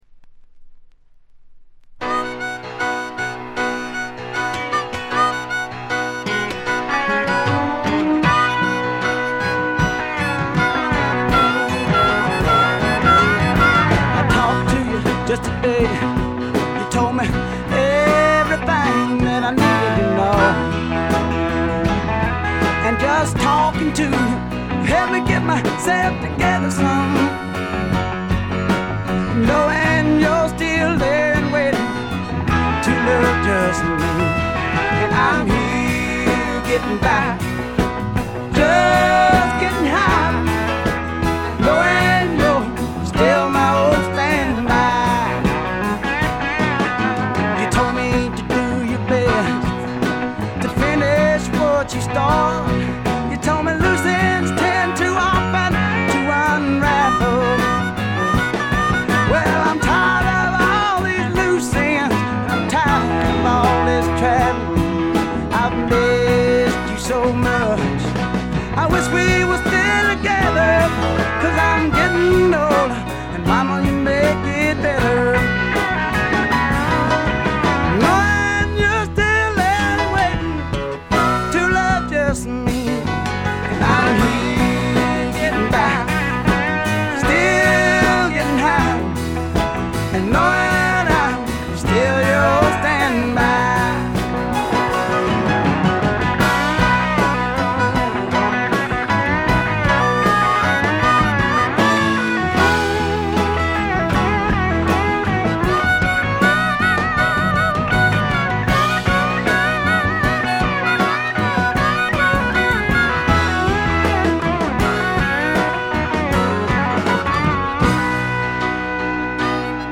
静音部での微細なバックグラウンドノイズ、散発的なプツ音軽いものが2回ほど。
びしっと決まった硬派なスワンプ・ロックを聴かせます。
試聴曲は現品からの取り込み音源です。
Recorded at Paramount Recording Studio.